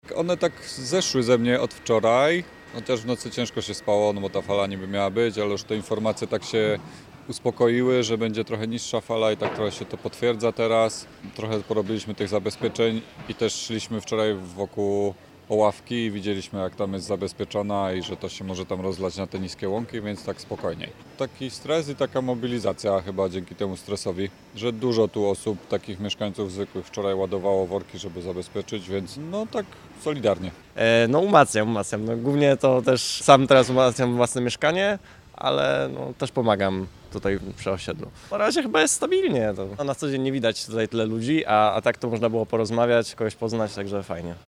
Jeden z mieszkańców osiedla opowiada, że choć początkowo emocje były bardzo silne, zwłaszcza nocą, gdy fala powodziowa miała nadejść, to późniejsze informacje uspokoiły sytuację.